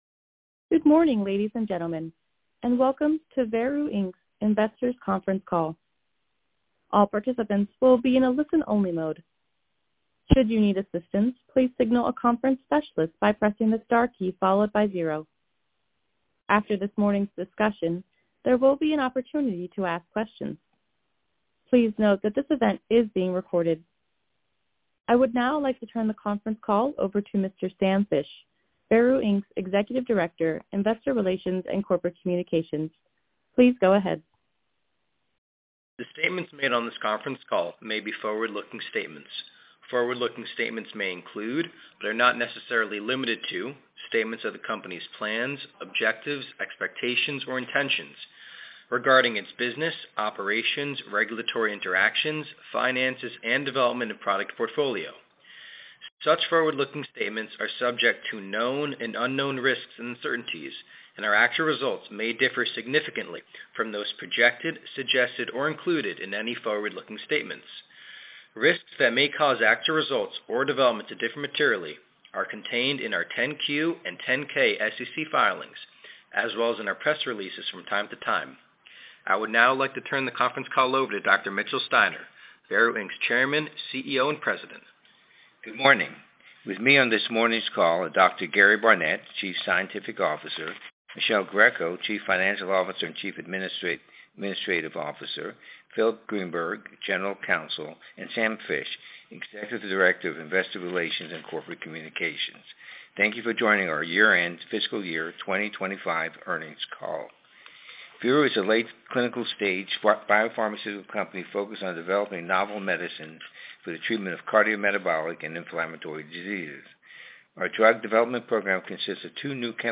FY 2025 Q4 Earnings Conference Call